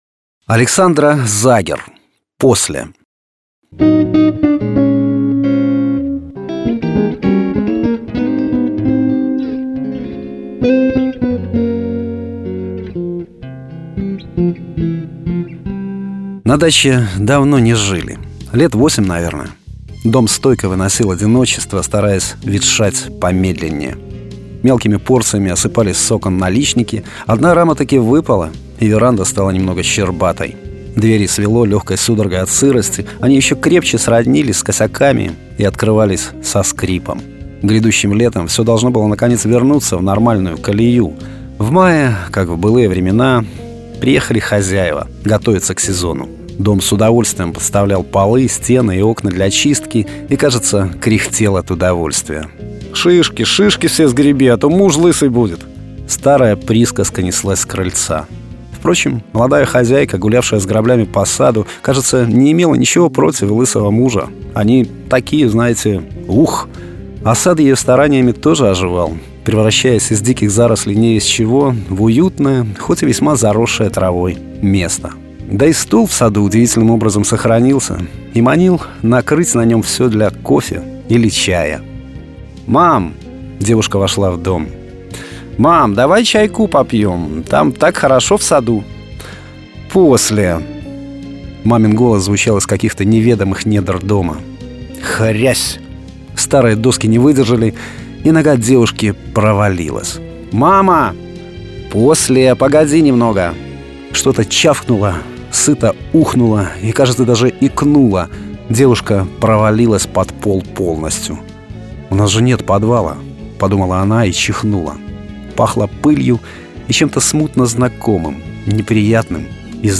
Аудиокниги
Аудио-Рассказы
Жанр: Современная короткая проза
Качество: mp3, 256 kbps, 44100 kHz, Stereo